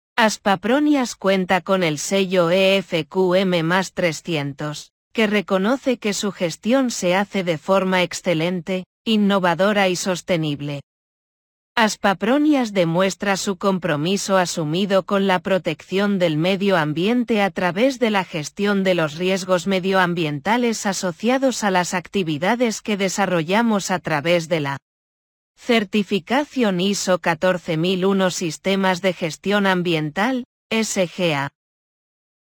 Lectura fácil